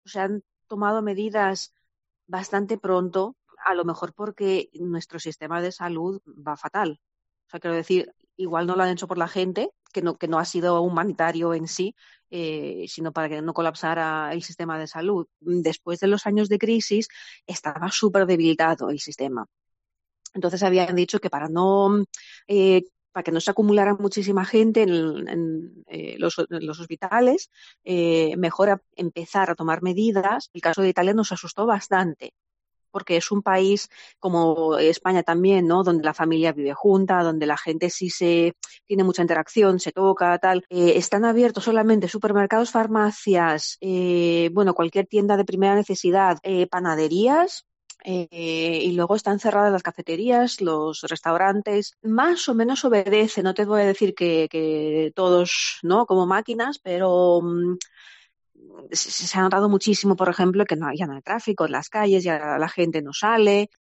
Una ciudadana griega cuenta a COPE que el gobierno argumentó que “no había muchas camas UCI y estaba muy debilitado el sistema”